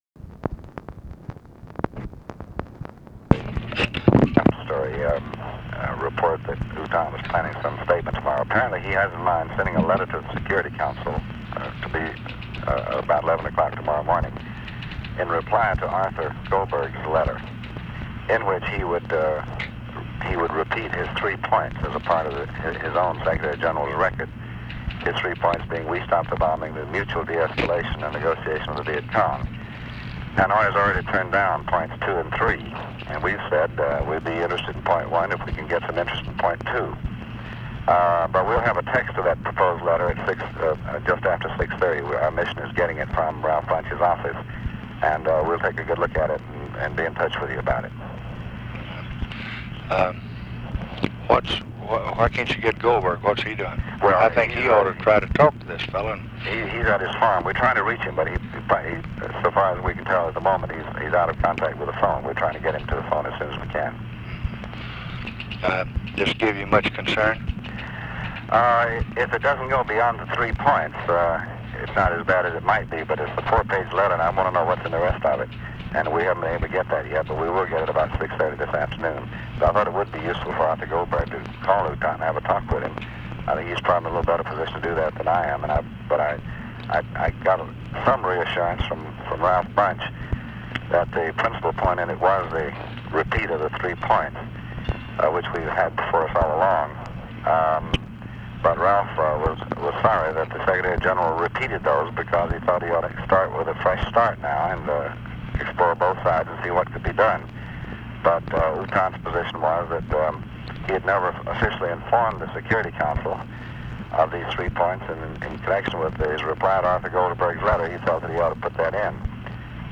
Conversation with DEAN RUSK, December 30, 1966
Secret White House Tapes